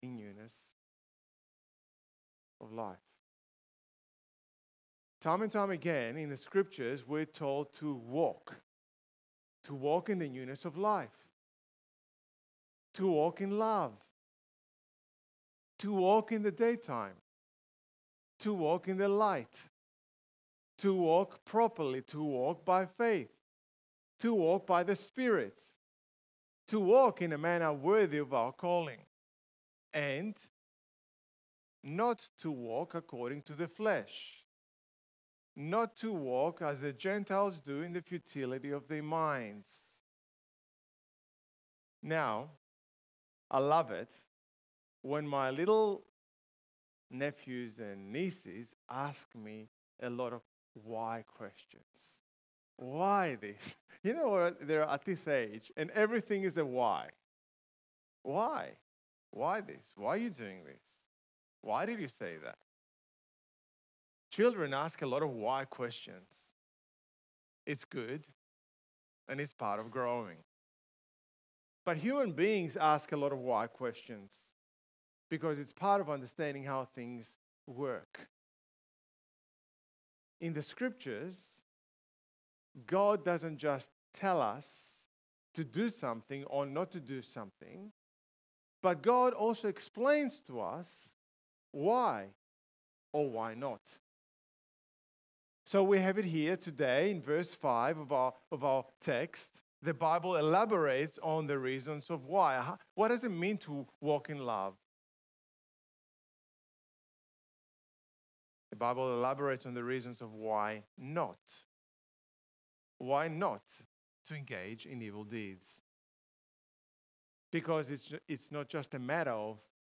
Recording started late and recorded at a very low volume
Sermon